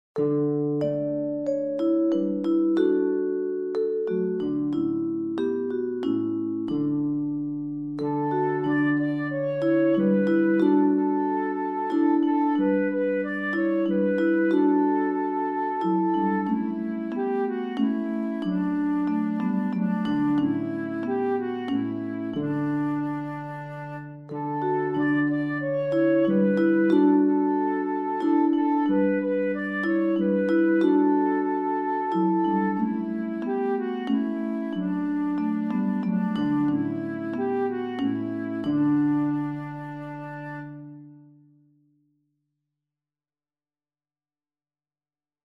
Langsam, feierlich
Langzaam, verheven